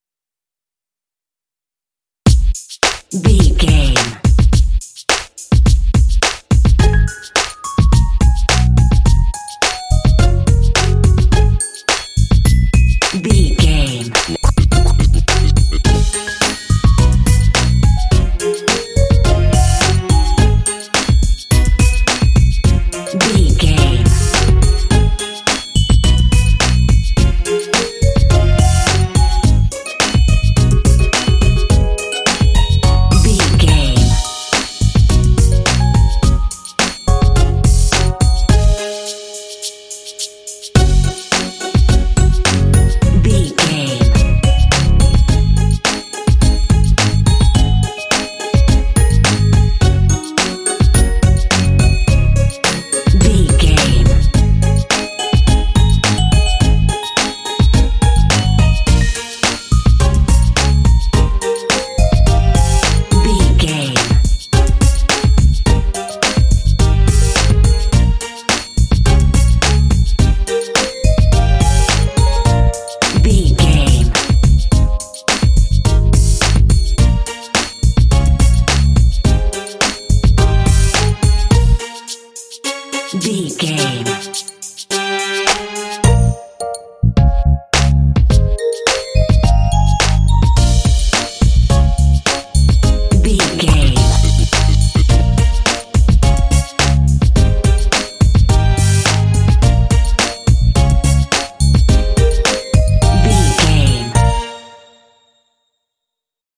Mixolydian
A♭
bouncy
groovy
piano
drums
strings
bass guitar